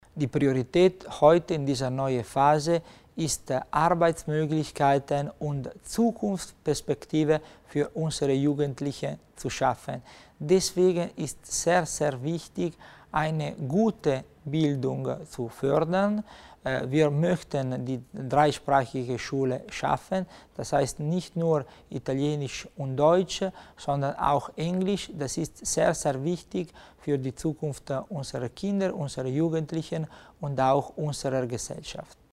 Landesrat Tommasini über die Maßnahmen im Bereich Schule
Südtirols Jugendliche müssen in Zukunft nicht nur zwei-, sondern dreisprachig sein: Für den italienischen Kulturlandesrat Christian Tommasini ist der umfassende und praxisnahe Spracherwerb der Schlüssel, um die Chancen junger Menschen am Arbeitsmarkt zu erhöhen. Tommasini kündigte heute (1. August) beim Mediengespräch zum Legislaturende an, dass er die Dreisprachigkeit stärken, Sprachaufenthalte im Ausland und Betriebspraktika ausbauen wolle.